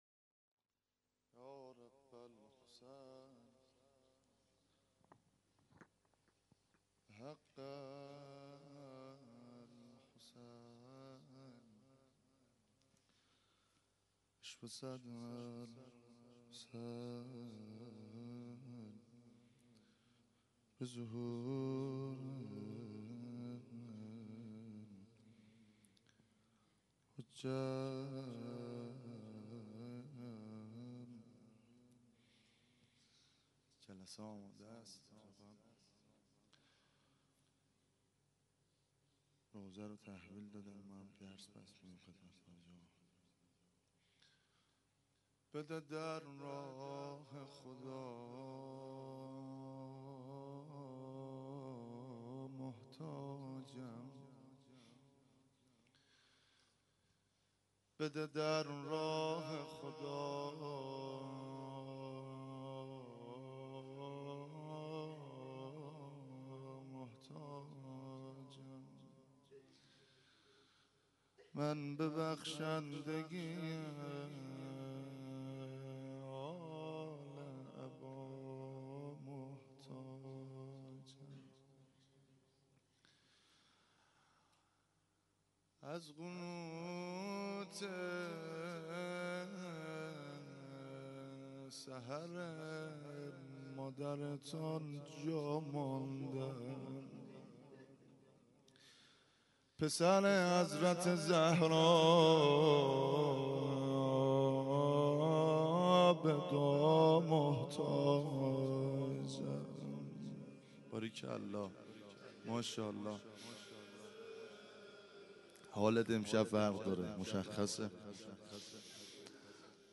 1- روضه حضرت مسلم (ع)